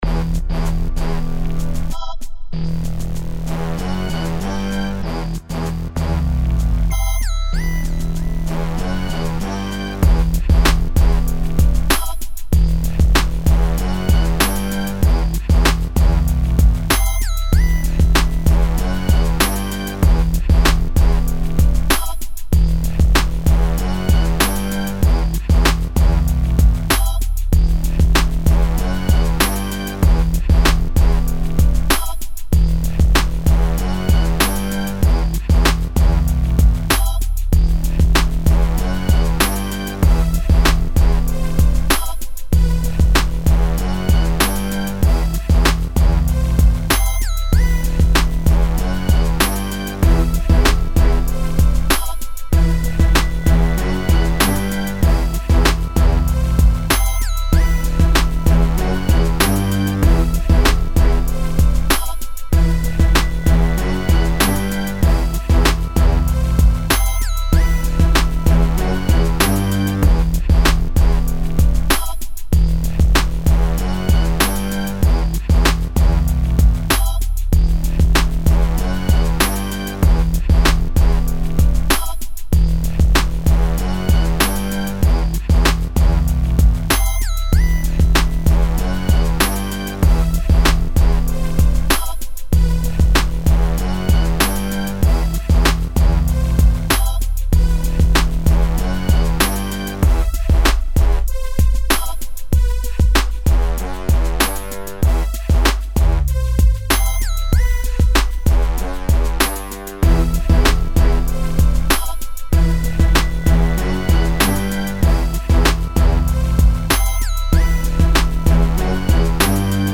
Grimy synths, epic strings and brass over double time drums.
80.1 BPM.